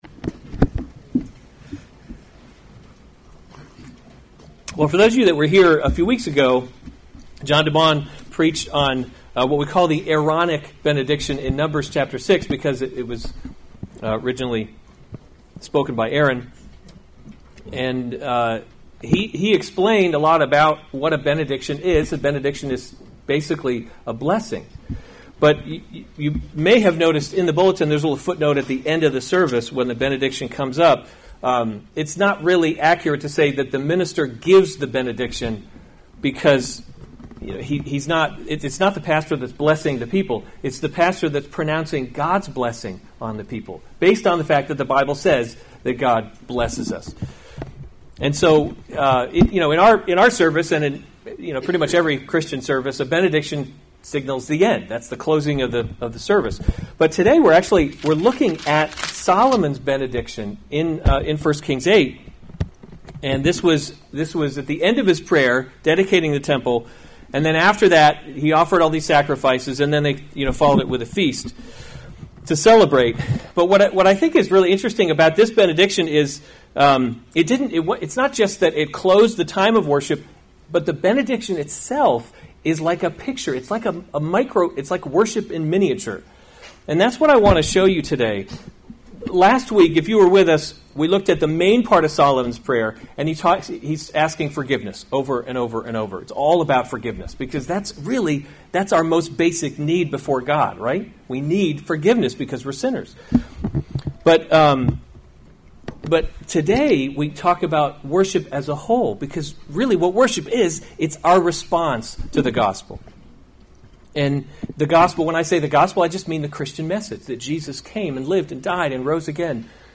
November 24, 2018 1 Kings – Leadership in a Broken World series Weekly Sunday Service Save/Download this sermon 1 Kings 8:54-66 Other sermons from 1 Kings Solomon’s Benediction 54 Now as […]